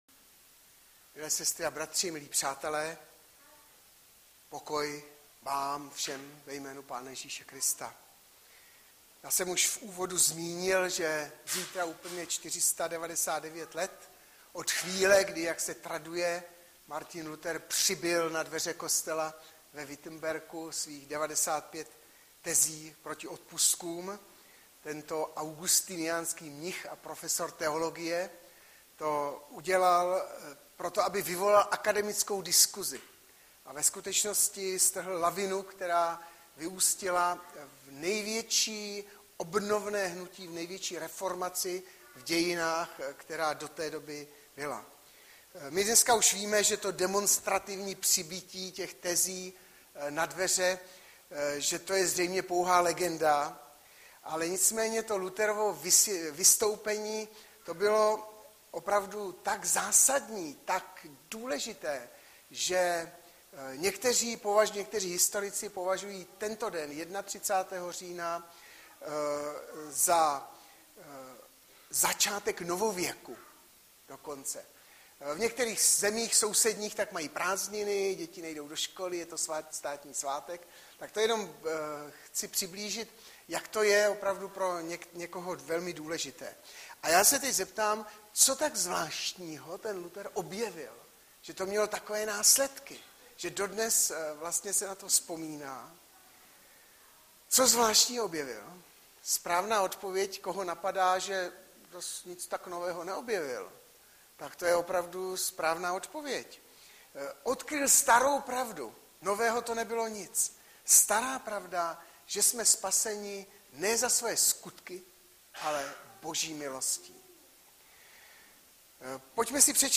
Audiozáznam kázání